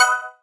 opponent_gem_collect.wav